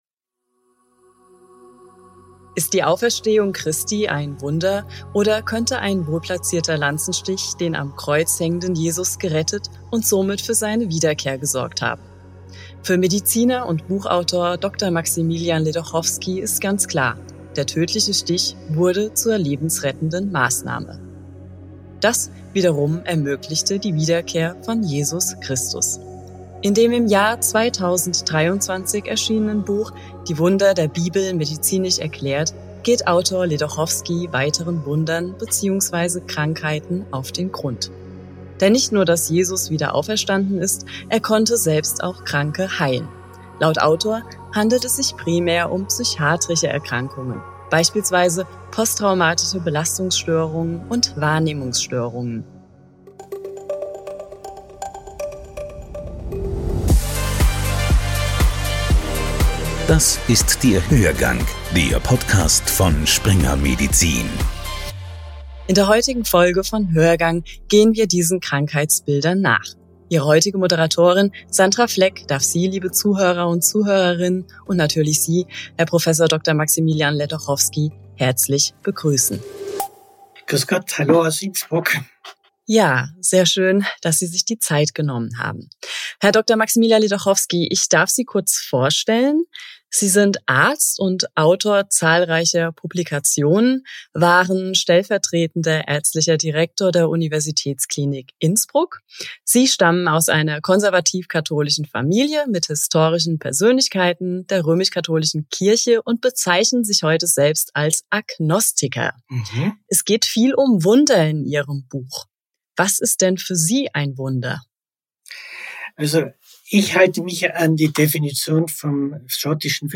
Diese Folge des Hörgangs kommt aus dem Narrenturm im Alten AKH in Wien – einem der faszinierendsten Museen Europas, berühmt für seine Sammlung medizinischer Kuriositäten.